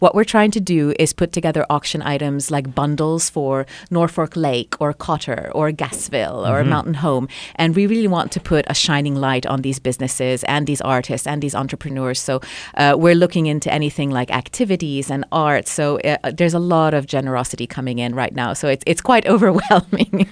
a recent guest of the Talk of the Town on KTLO-FM